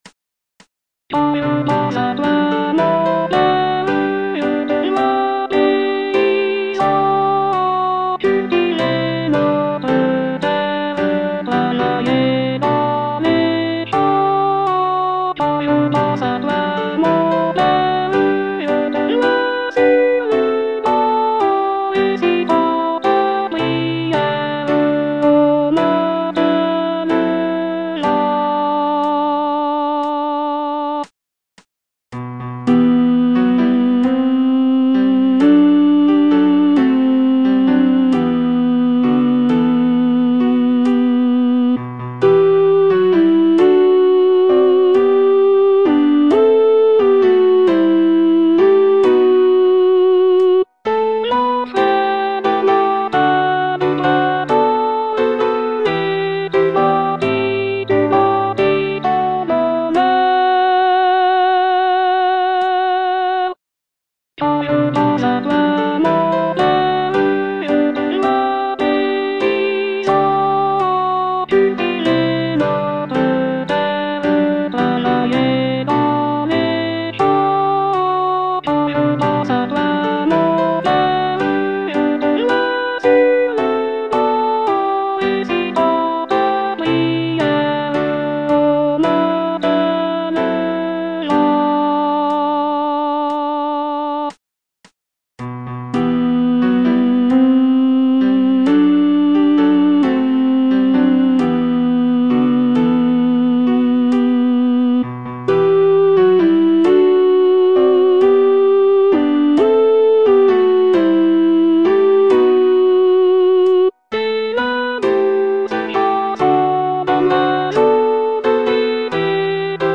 Alto I (Voice with metronome)